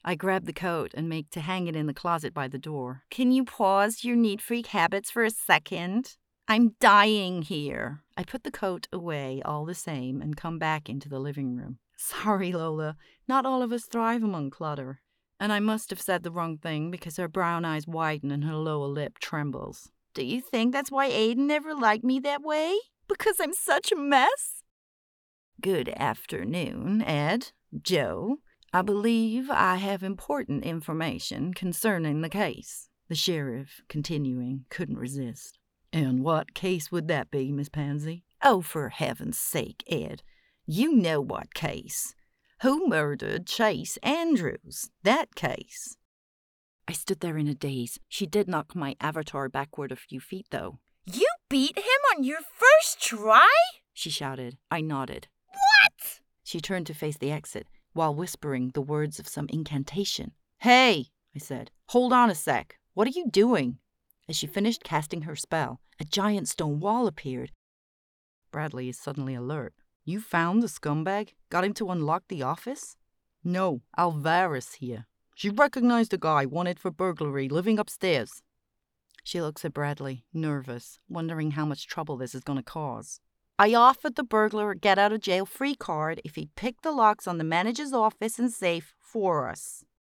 • Home Studio